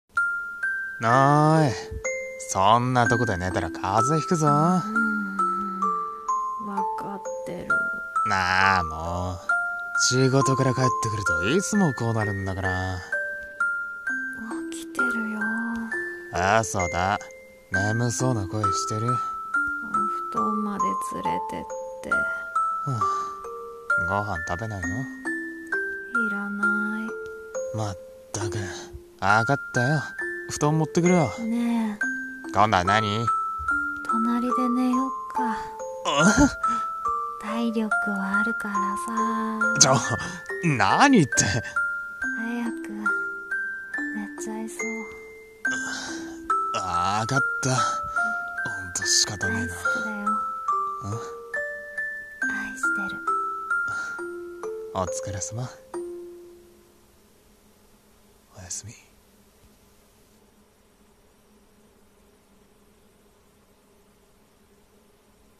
【声劇】